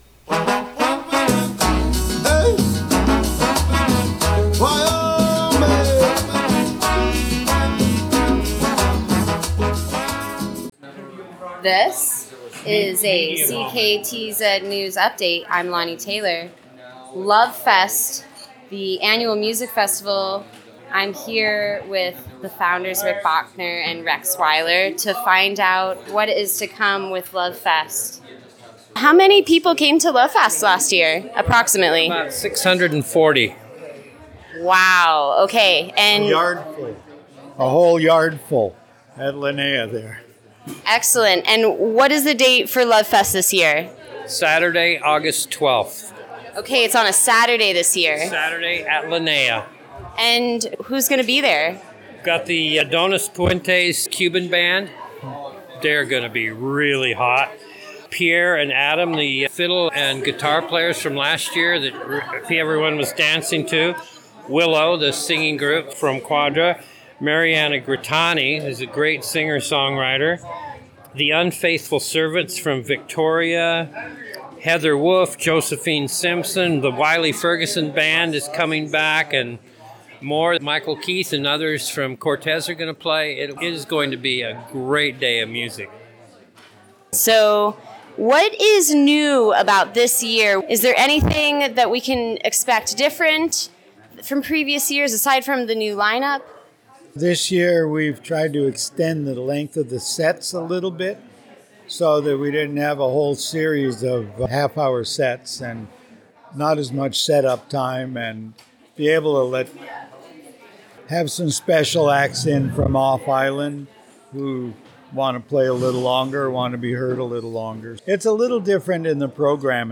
CKTZ-News-Lovefest-Ups-the-talent.mp3